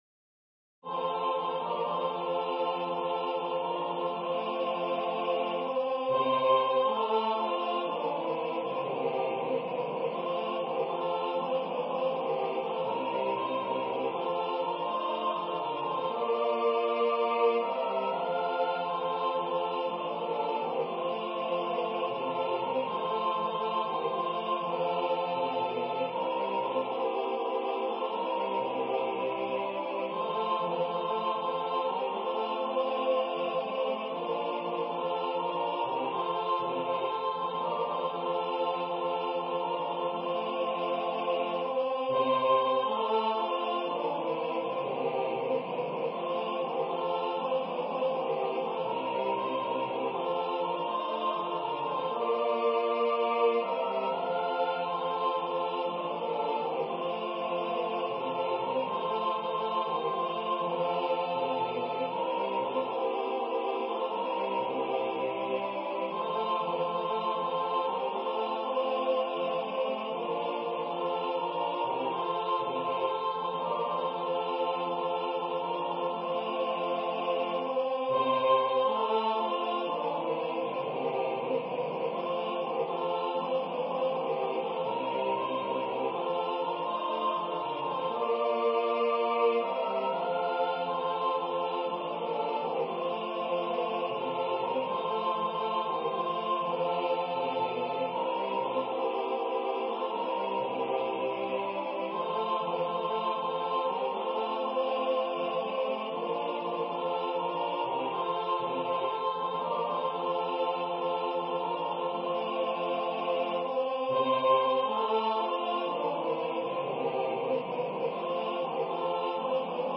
The Introit's opening theme is heard for the last time, and the work ends in a joyful rhapsody.